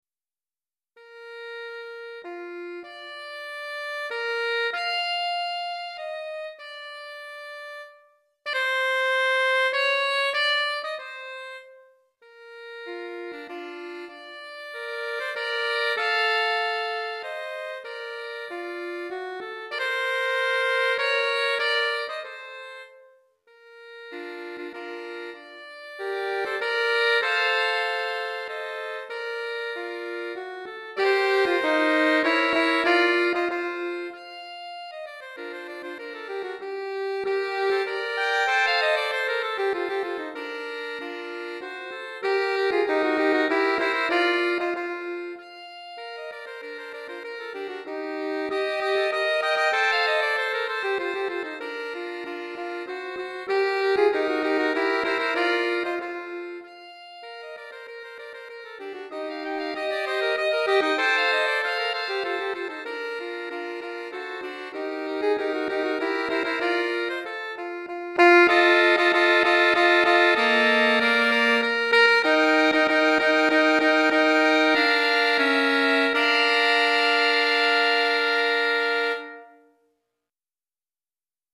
3 Saxophones